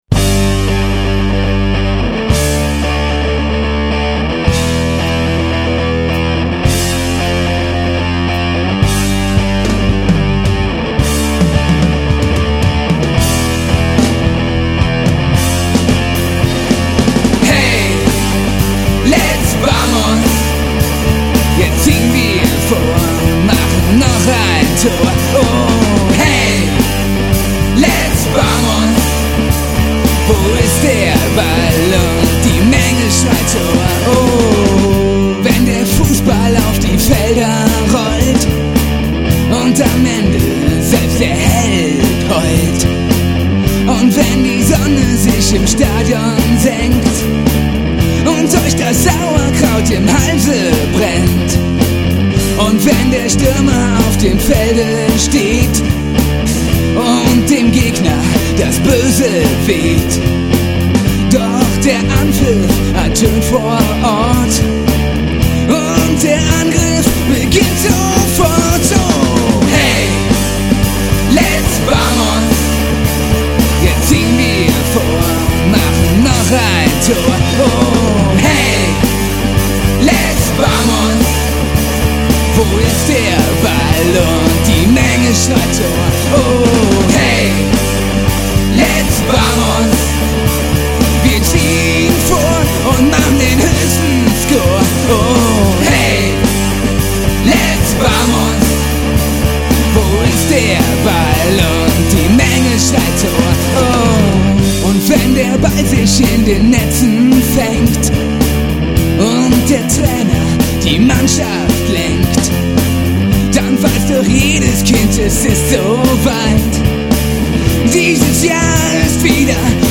German Rock made in Costa Rica